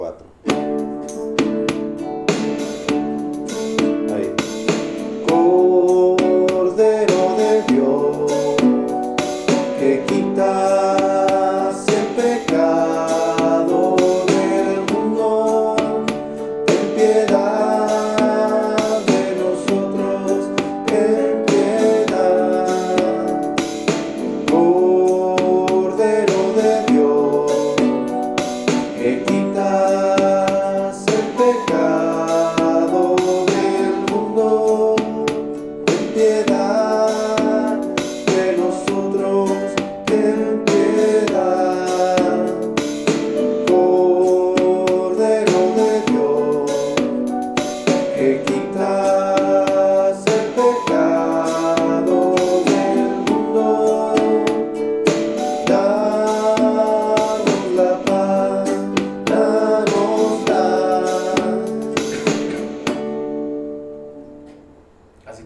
Ritmo: 45A Tempo: 100